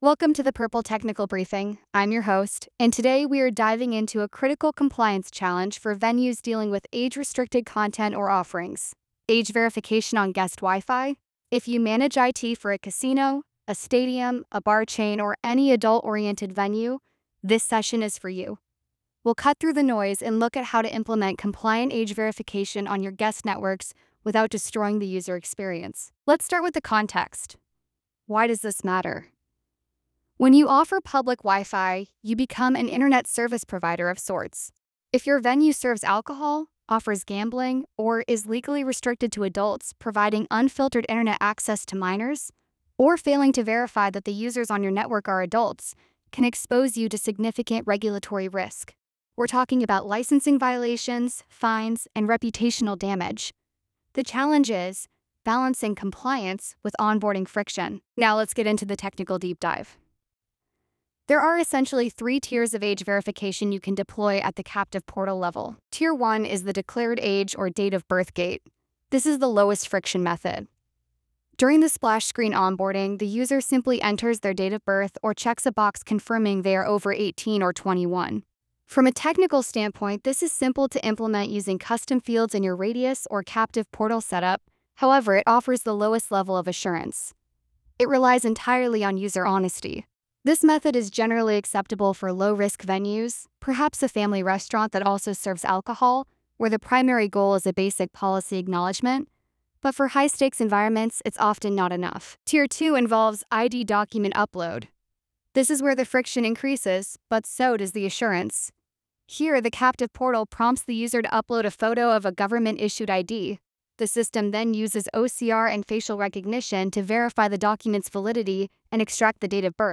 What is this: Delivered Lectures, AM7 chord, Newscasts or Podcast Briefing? Podcast Briefing